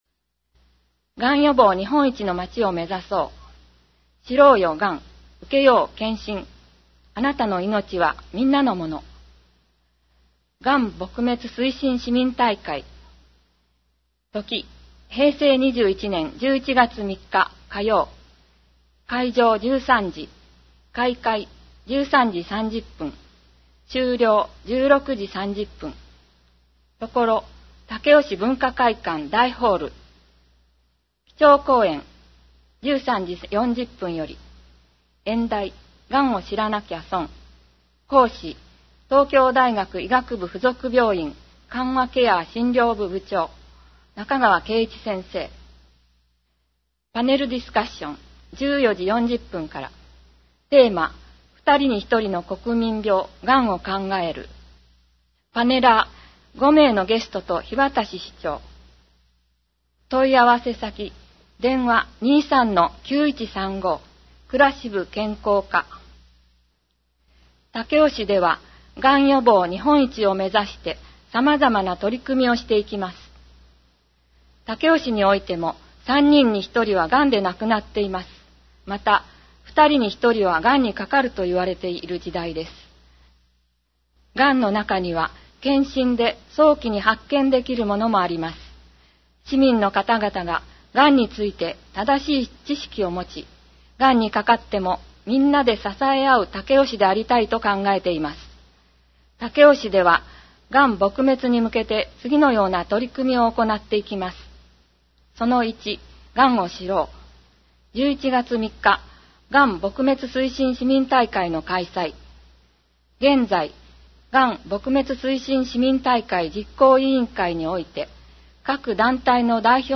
また、音訳ボランティア「エポカル武雄フレンズ」のご協力により、音読データをMP3形式で提供しています。